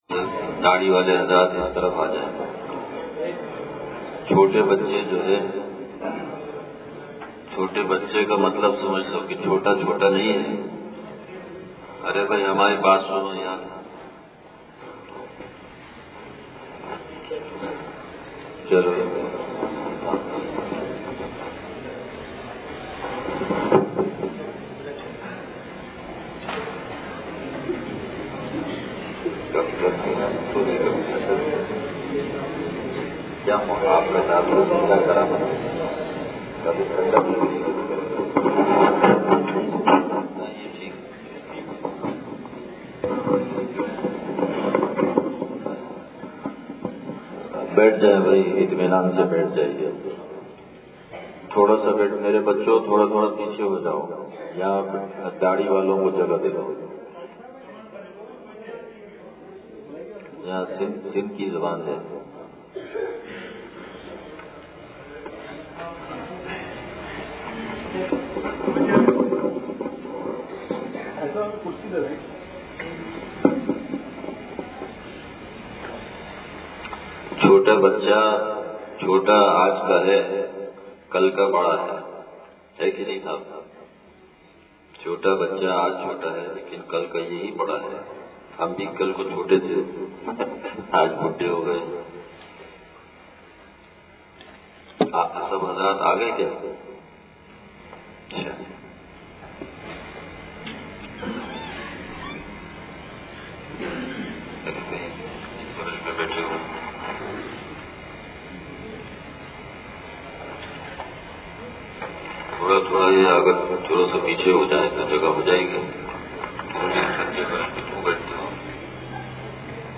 دار العلوم عزیزیہ حسینہ دوڑ نواب شاہ سندھ (قبل ظہر بیان)